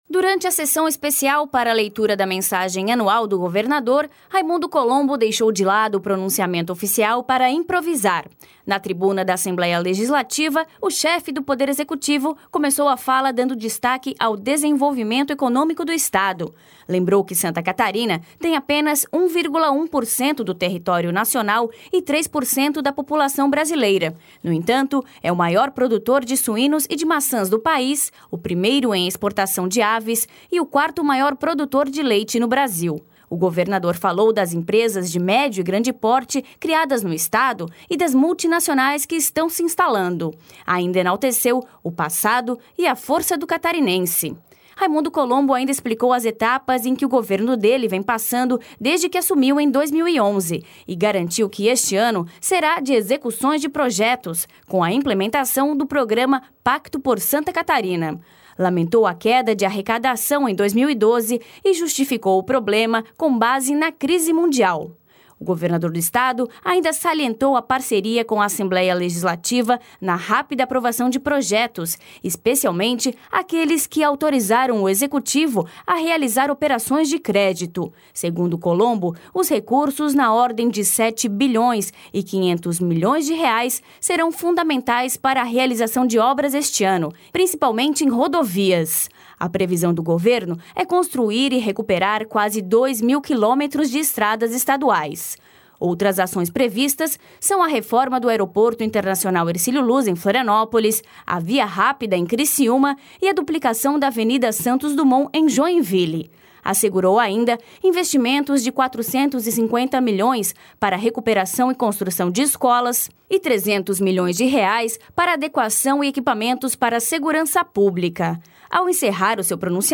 Durante a Sessão Especial para a leitura da mensagem anual do governador, Raimundo Colombo (PSD) deixou de lado o pronunciamento oficial para improvisar. Na tribuna da Assembleia Legislativa, o chefe do Poder Executivo começou a fala dando destaque ao desenvolvimento econômico do estado.